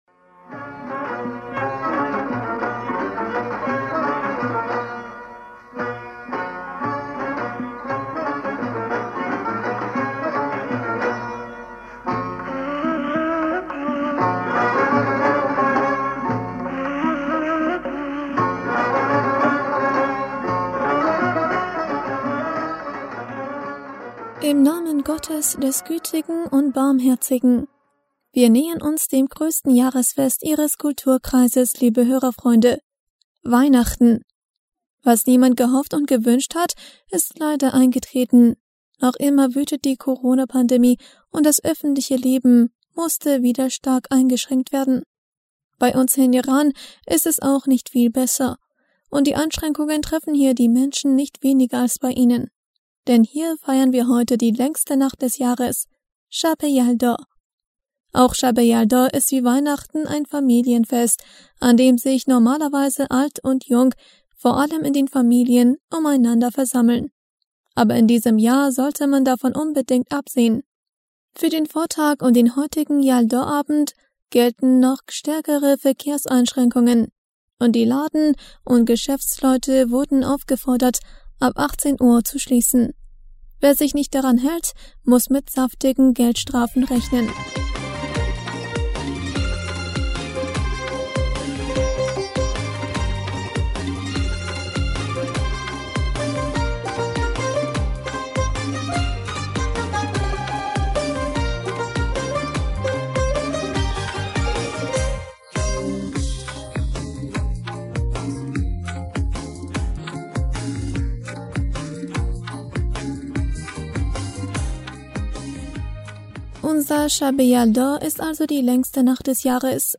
Hörerpostsendung am 20. Dezember 2020 Bismillaher rahmaner rahim - Wir nähern uns dem größten Jahresfest Ihres Kulturkreises liebe Hörerfreunde – Weihnach...